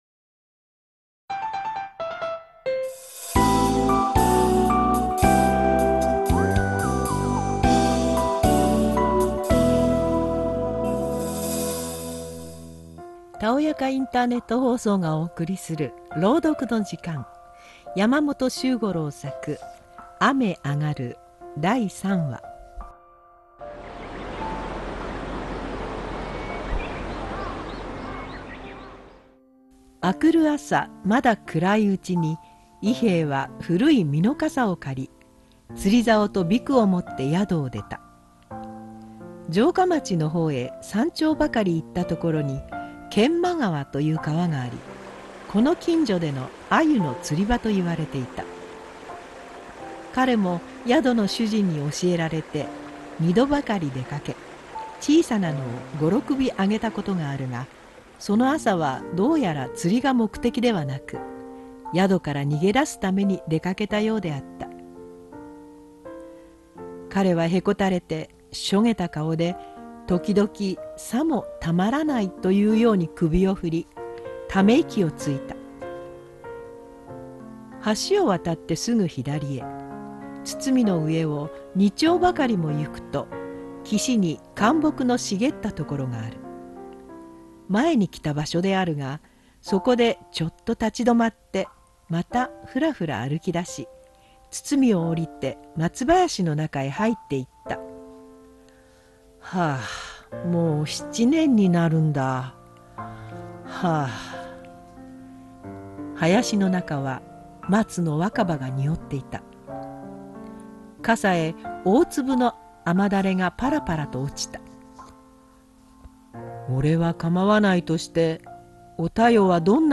たおやかインターネット放送 - （たおやかインターネット放送)朗読の時間作：山本周五郎雨あがる第3話